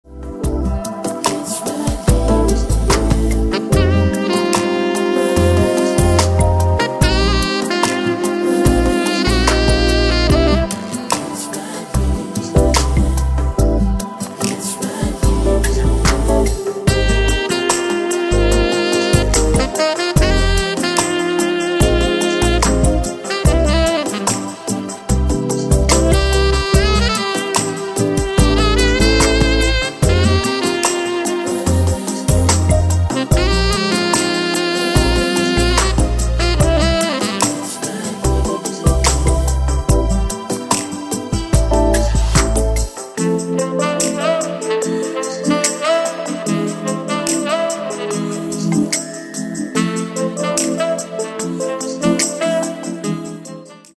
Saxophon & coole Sounds
Tenor-Saxophon